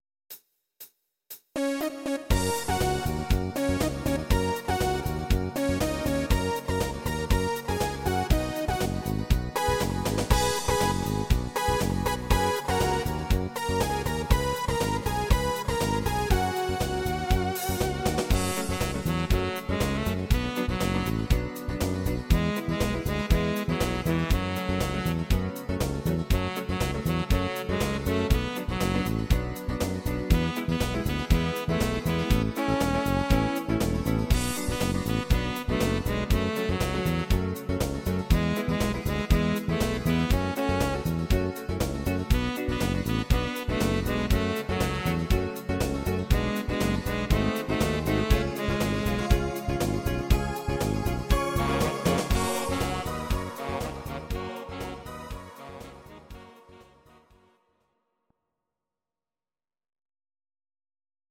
Audio Recordings based on Midi-files
Oldies, German